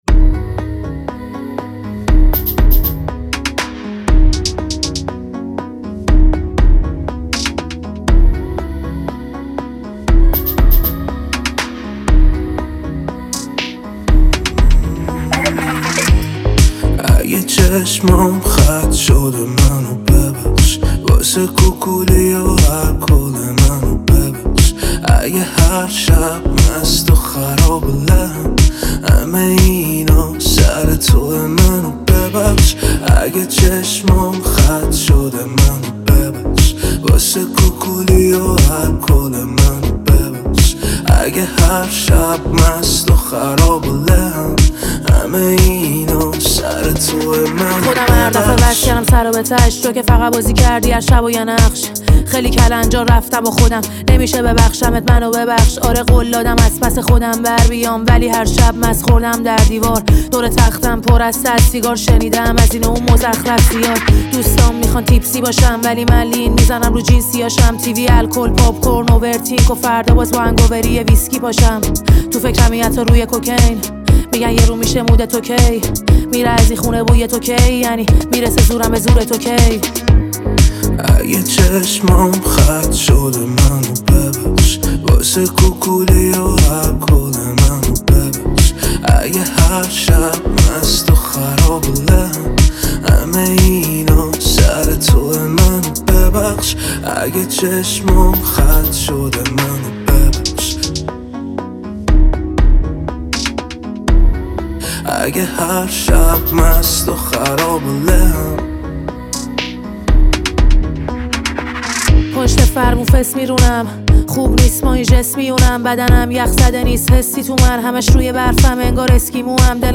اهنگ جدید رپ